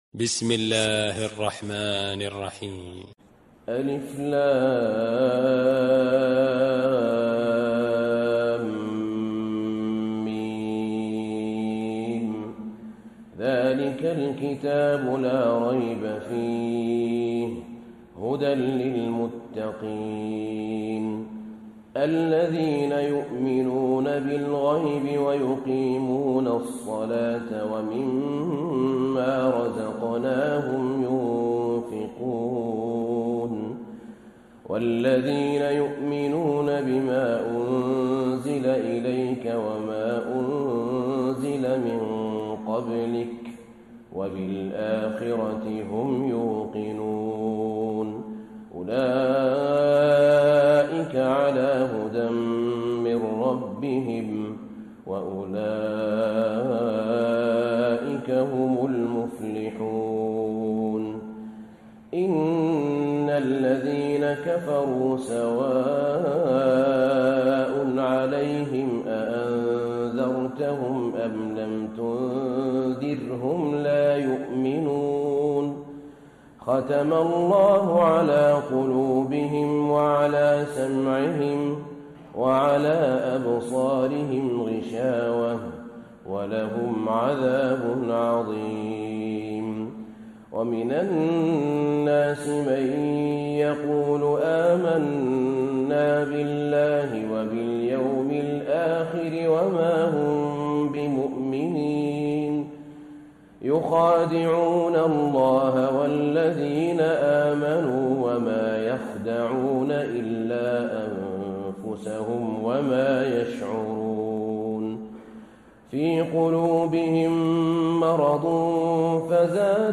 تهجد ليلة 21 رمضان 1434هـ من سورة البقرة (1-91) Tahajjud 21 st night Ramadan 1434H from Surah Al-Baqara > تراويح الحرم النبوي عام 1434 🕌 > التراويح - تلاوات الحرمين